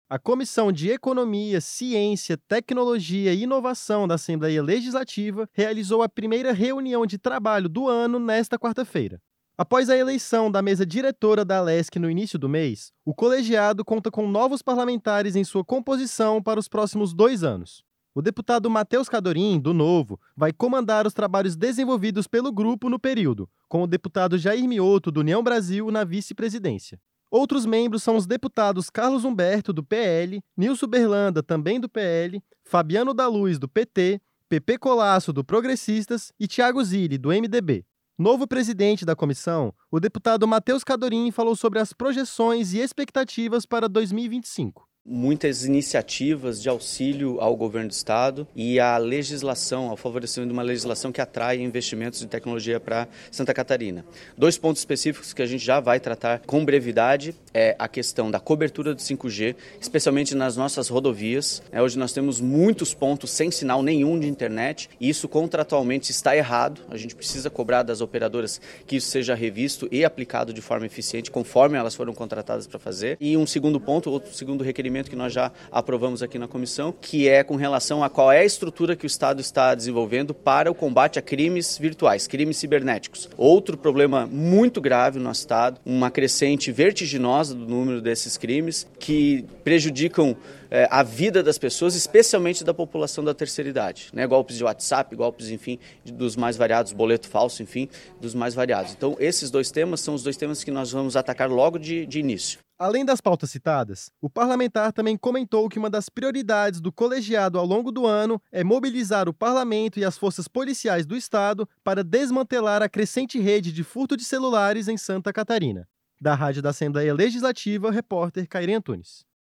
Entrevista com:
- deputado Matheus Cadorin (Novo), presidente da Comissão de Economia, Ciência, Tecnologia e Inovação.